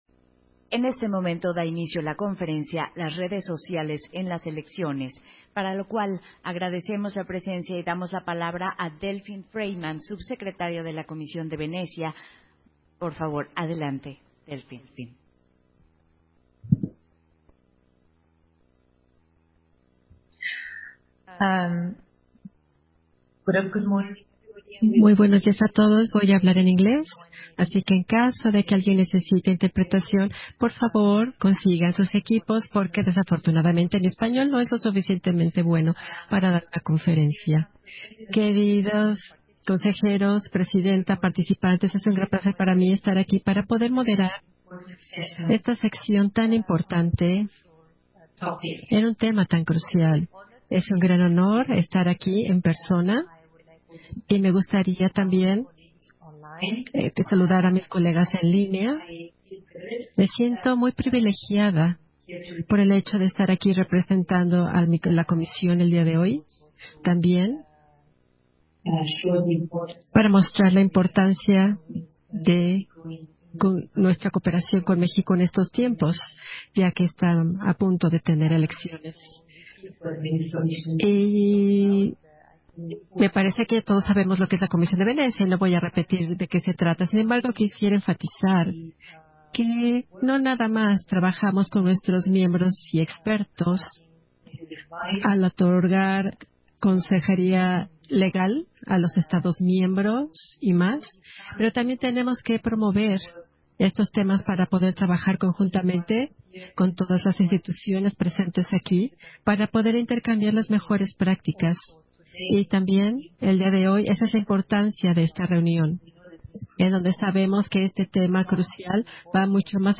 041223_AUDIO_041223_VE_CONFERENCIA-LAS-REDES-SOCIALES-EN-LAS-ELECCIONES
Versión estenográfica de la conferencia, Las redes sociales en las elecciones, en el marco de la II Cumbre de la Democracia Electoral